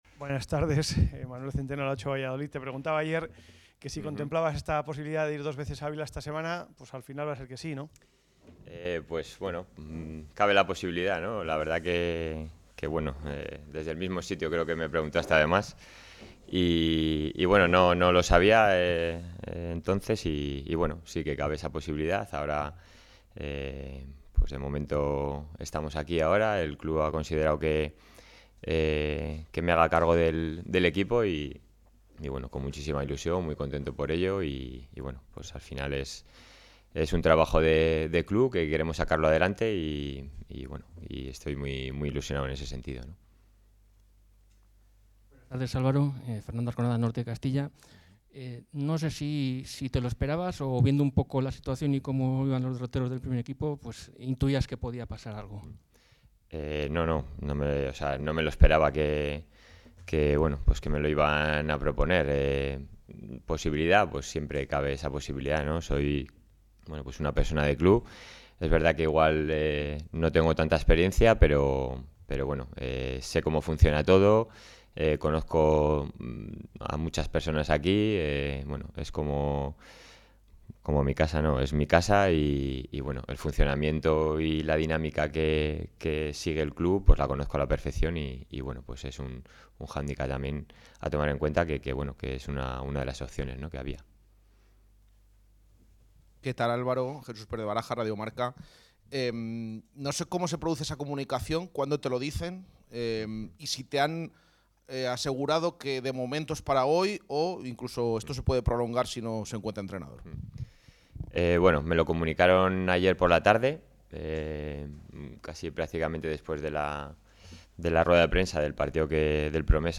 aquí la rueda de prensa completa.